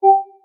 beep.ogg